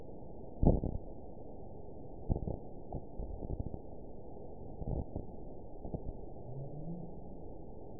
event 914355 date 05/06/22 time 00:01:14 GMT (3 years ago) score 9.40 location TSS-AB08 detected by nrw target species NRW annotations +NRW Spectrogram: Frequency (kHz) vs. Time (s) audio not available .wav